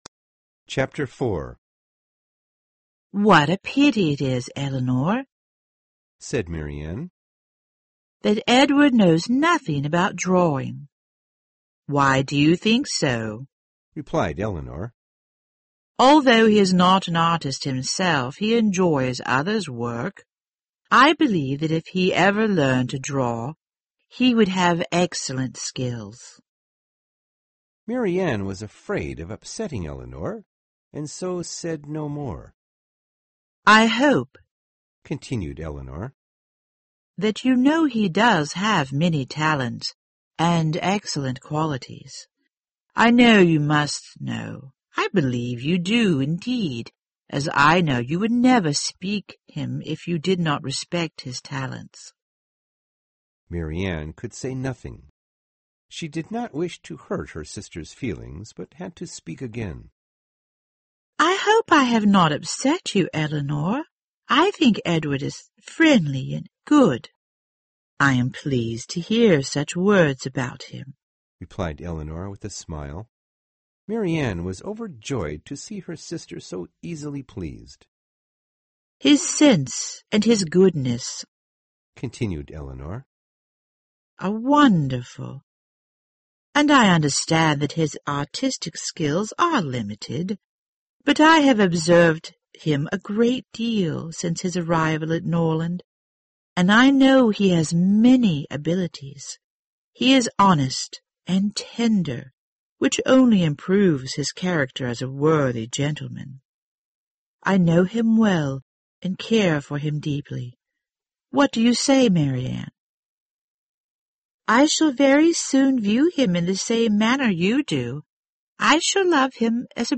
有声名著之理智与情感 04 听力文件下载—在线英语听力室